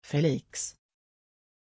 Pronuncia di Feliks
pronunciation-feliks-sv.mp3